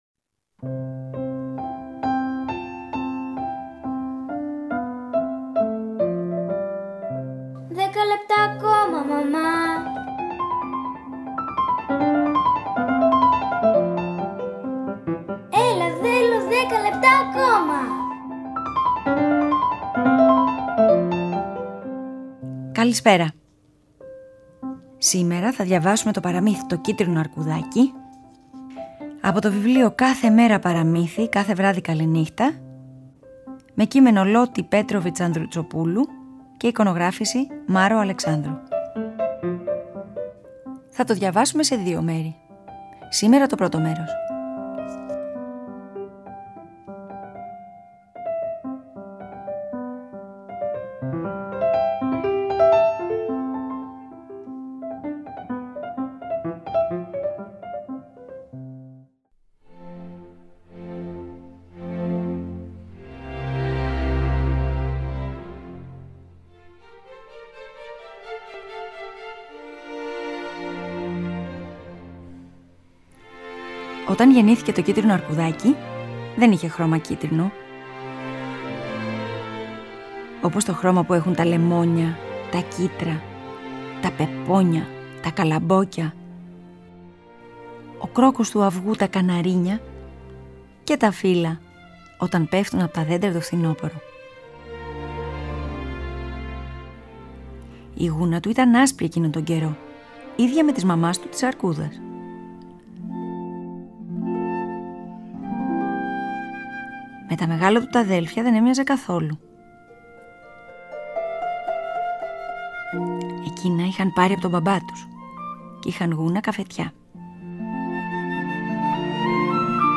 Σήμερα στις 20:00 στο Τρίτο Πρόγραμμα 90,9, θα διαβάσουμε την ιστορία «Το κίτρινο αρκουδάκι» με κείμενο Λότη Πέτροβιτς-Loty Petrovits, εικόνες Μάρω Αλεξάνδρου, από τις Εκδόσεις Πατάκη-Patakis Publishers
Θα το διαβάσουμε σε δύο μέρη.